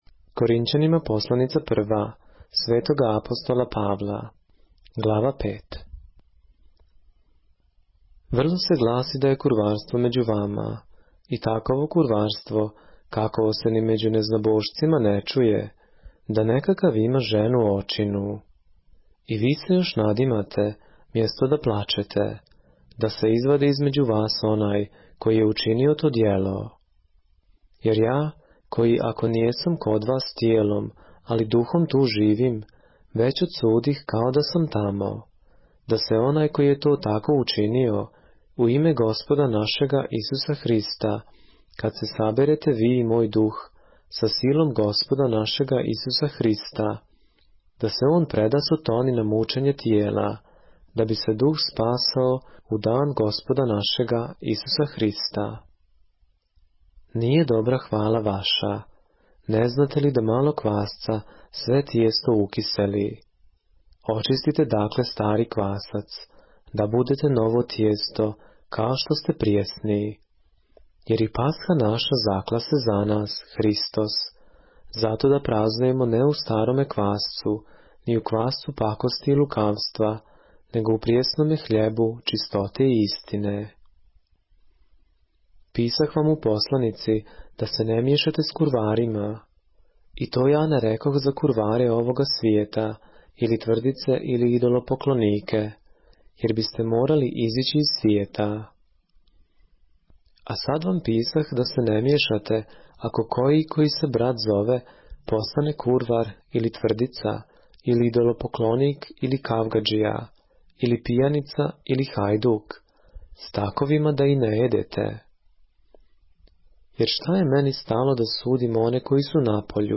поглавље српске Библије - са аудио нарације - 1 Corinthians, chapter 5 of the Holy Bible in the Serbian language